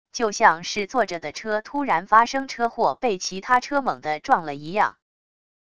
就像是坐着的车突然发生车祸被其他车猛的撞了一样wav音频